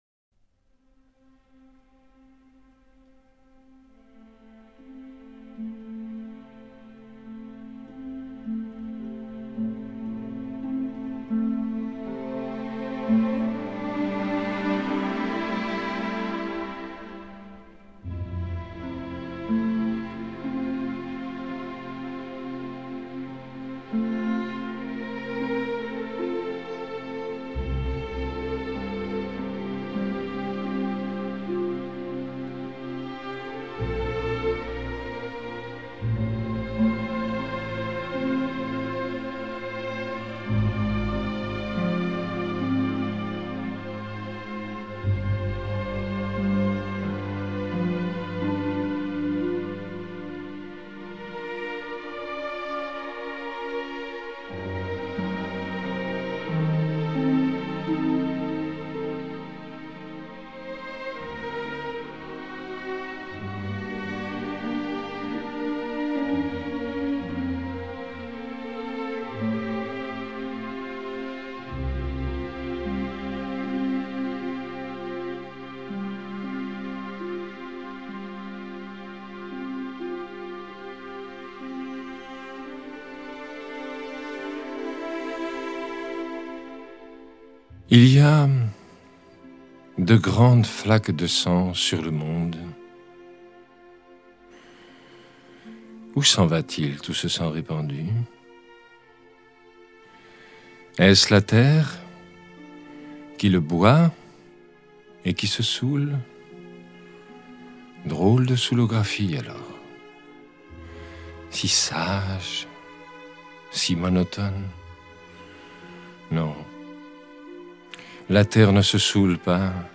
sur l'Adagietto de la Ve Symphonie de Gustav Mahler